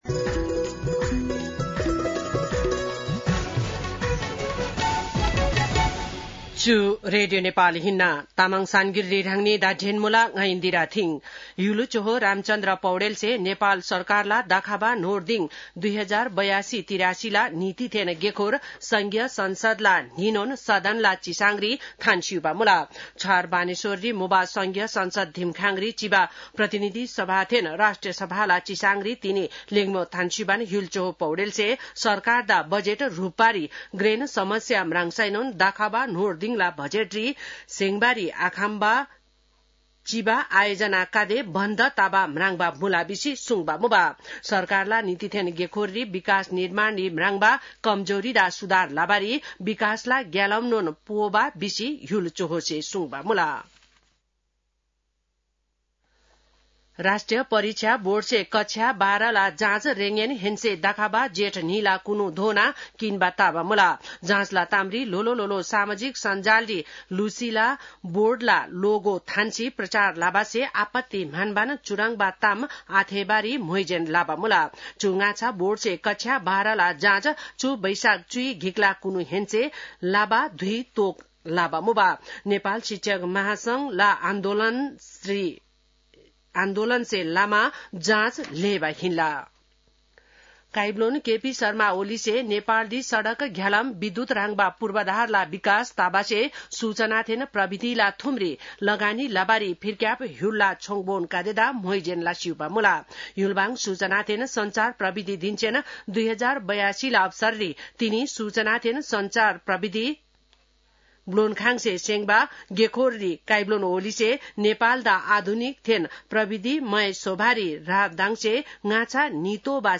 तामाङ भाषाको समाचार : १९ वैशाख , २०८२